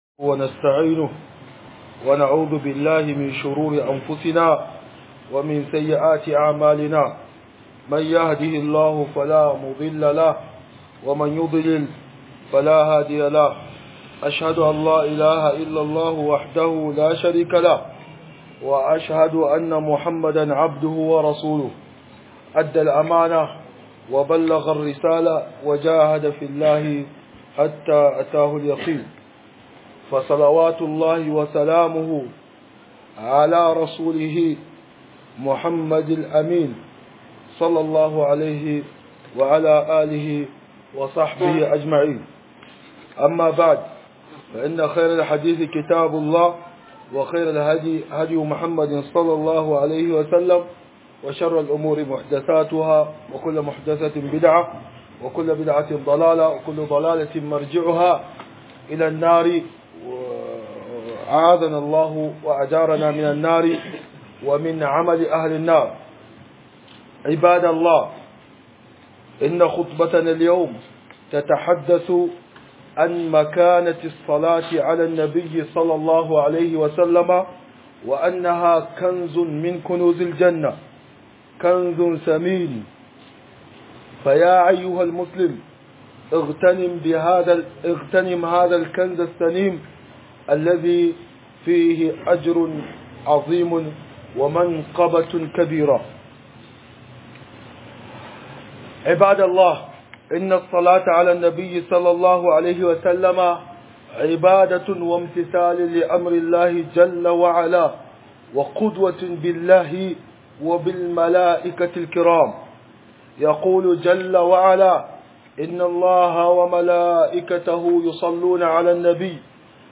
خطبة_الجمعة_بعنوان_الصلاة_على_النبي_صَلَّى_الله_عليه_وسلم_كنز_ثمين